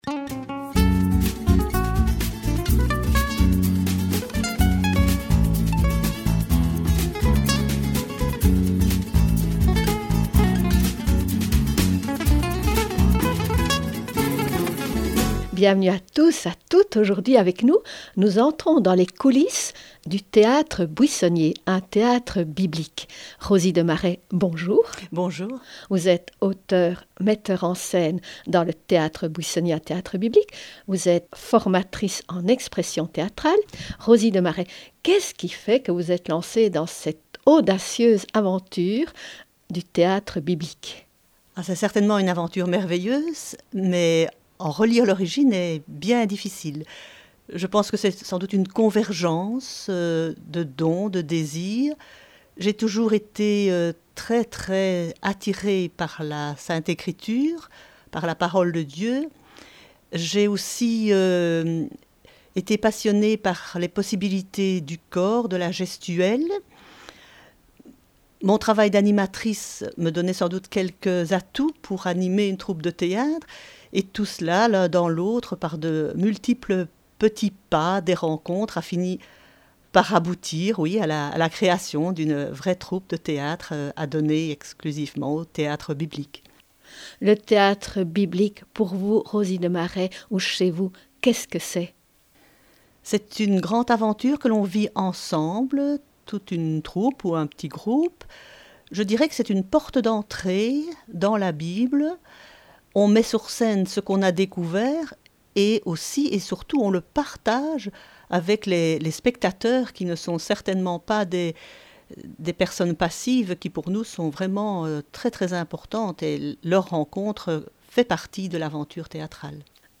une interview pour RCF Sud-Belgique